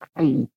Звуки глотания
Человек еле-еле проглотил огромный кусок